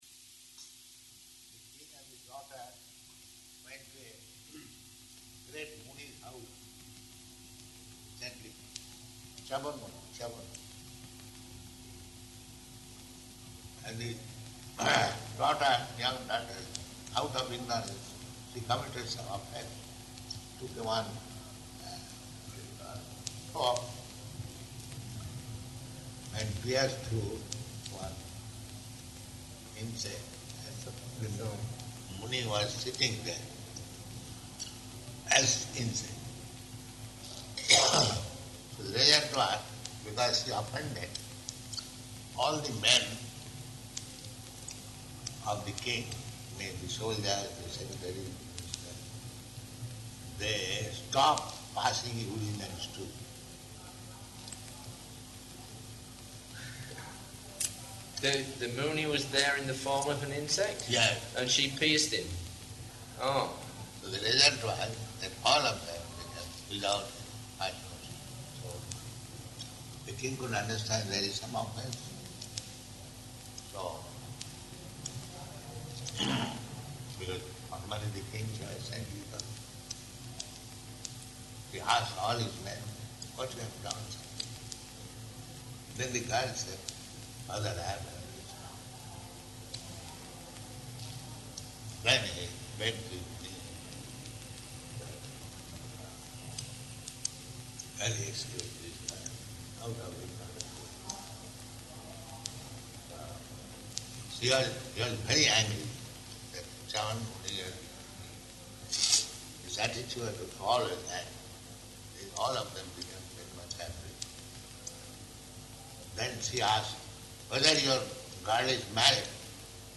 Room Conversation
Room Conversation --:-- --:-- Type: Conversation Dated: September 9th 1976 Location: Vṛndāvana Audio file: 760909R2.VRN.mp3 Prabhupāda: The king and the daughter went to a great muni's house, a saintly person.